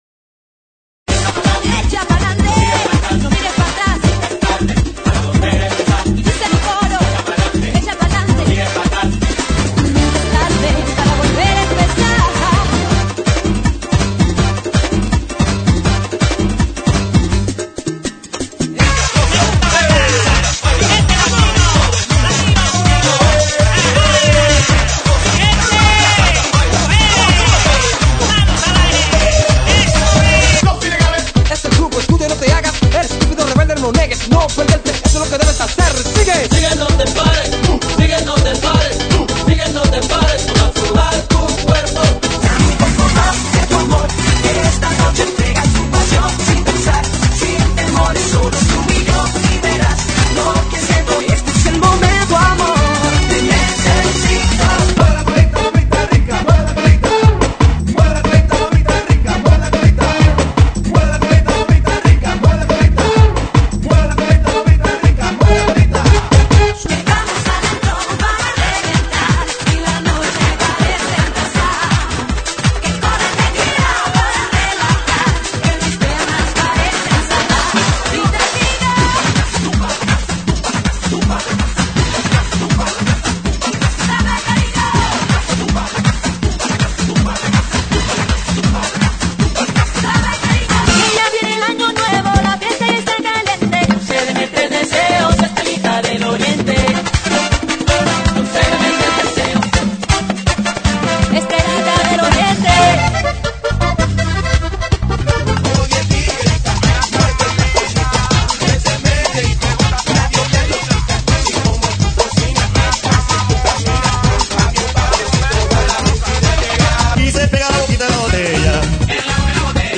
GENERO: LATINO